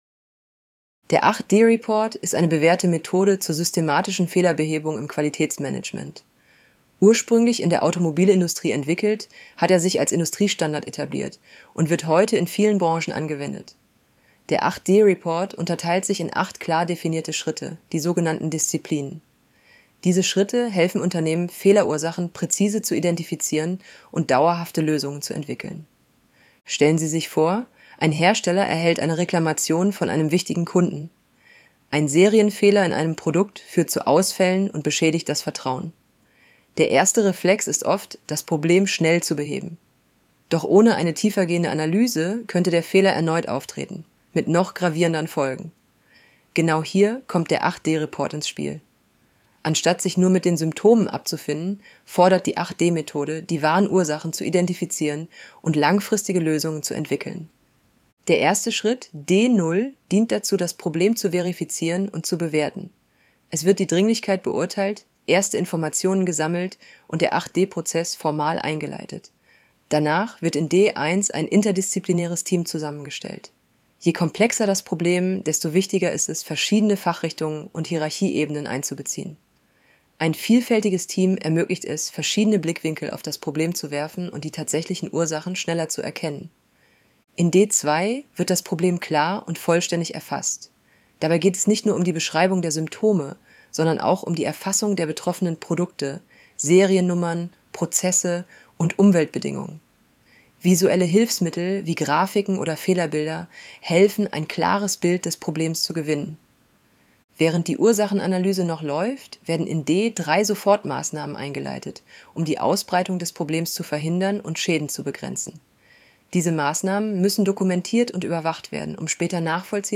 Hinweis: Diese Vorlesefunktion verwendet eine synthetisch erzeugte Stimme aus einem KI-System.
Die Stimme ist keine Aufnahme einer realen Person.